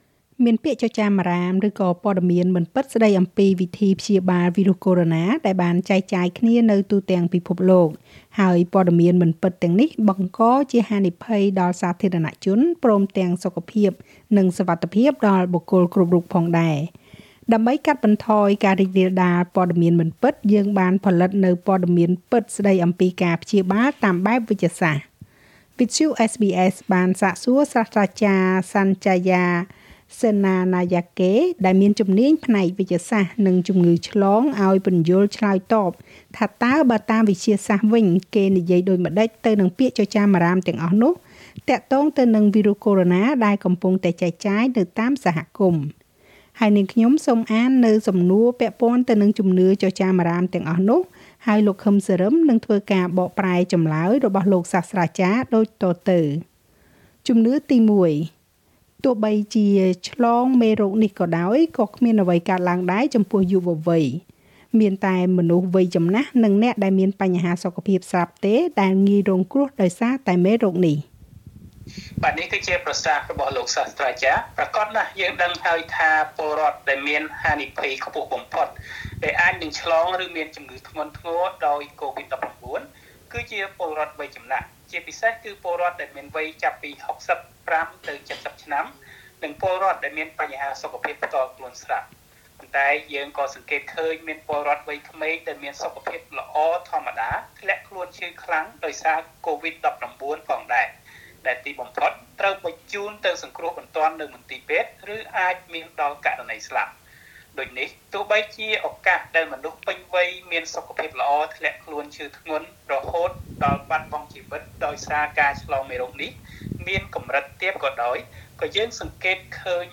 coronavirus_myth_qa.mp3